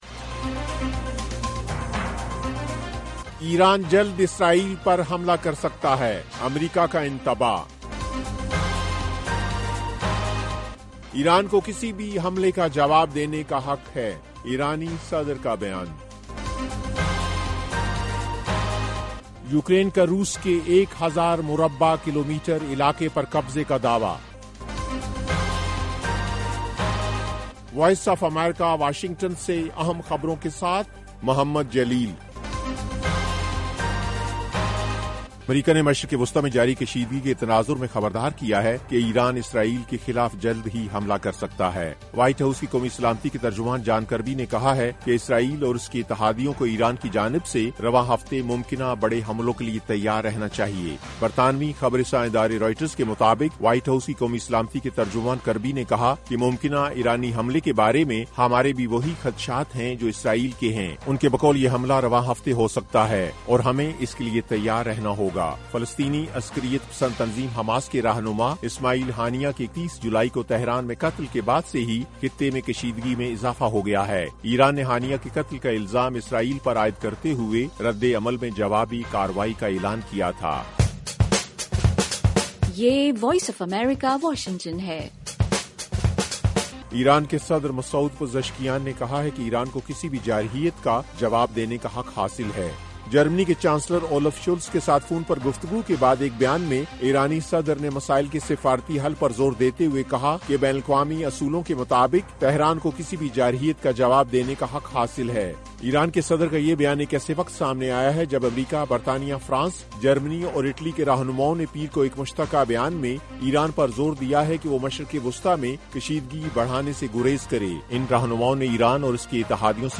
ایف ایم ریڈیو نیوز بلیٹن: شام 6 بجے